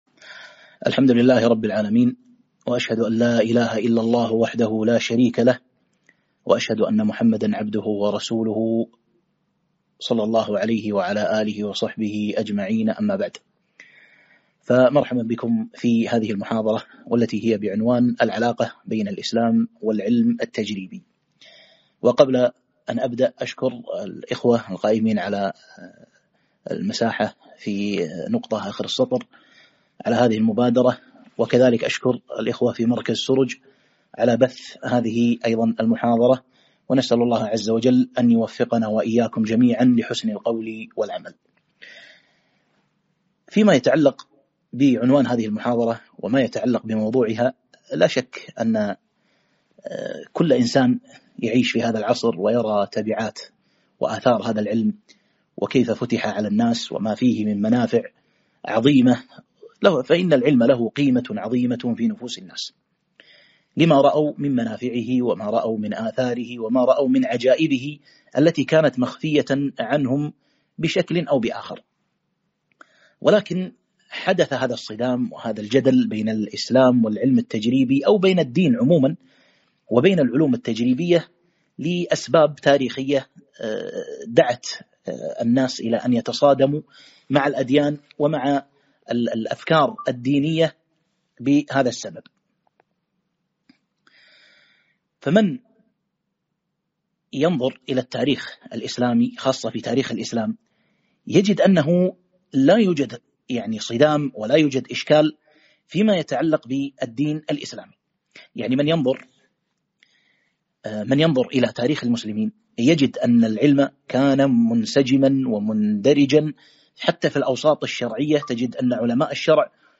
محاضرة - العلاقة بين الإسلام والعلم التجريبي